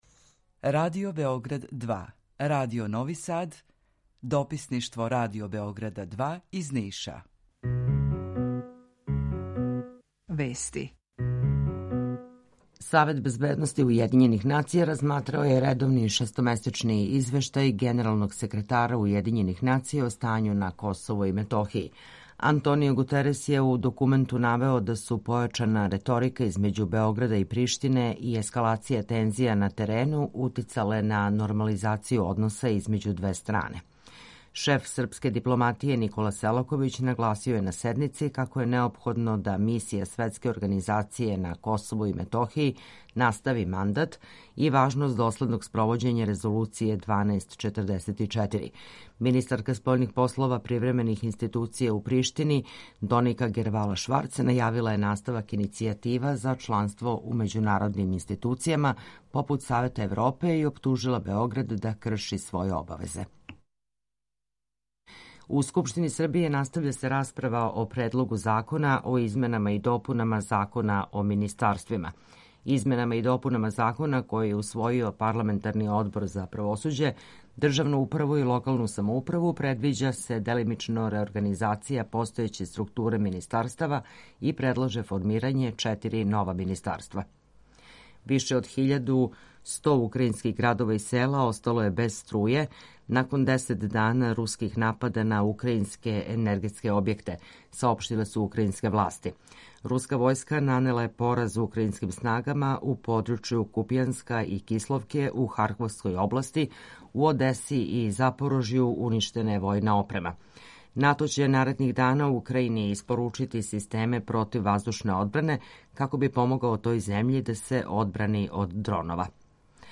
Укључење Радио Грачанице
У два сата, ту је и добра музика, другачија у односу на остале радио-станице.